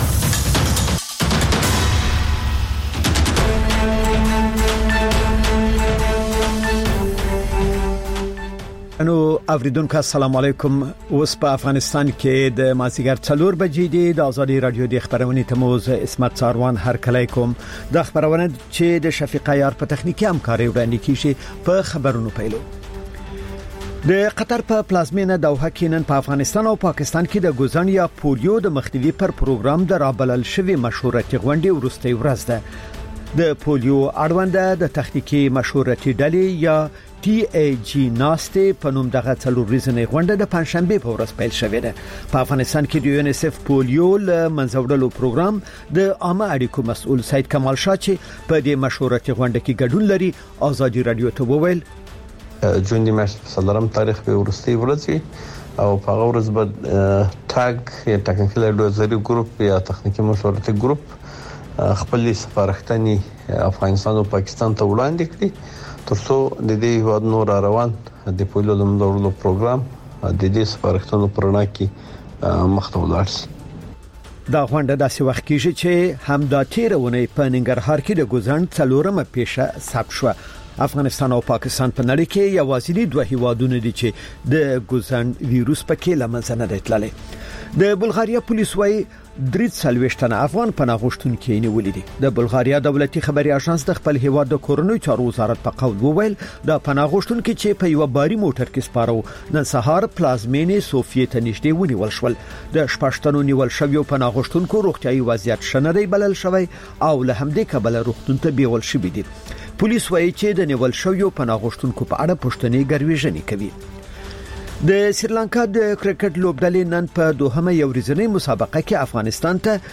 پخش زنده - رادیو آزادی